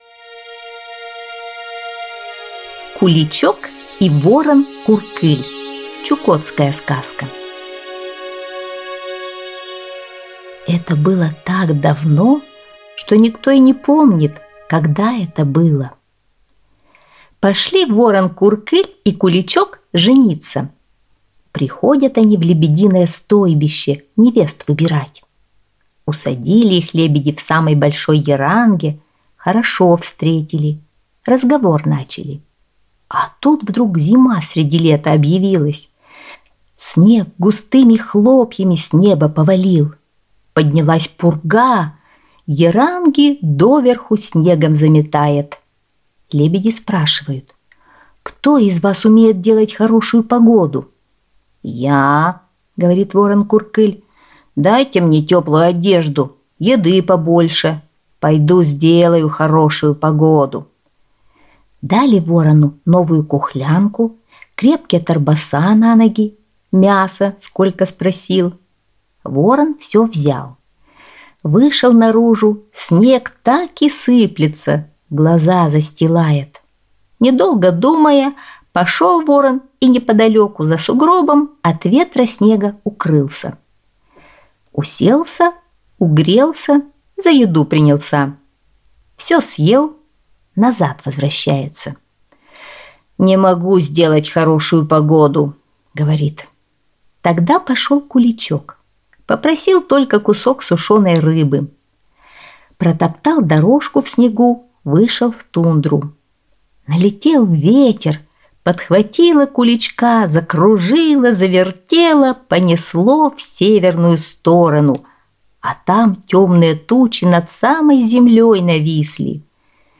Чукотская аудиосказка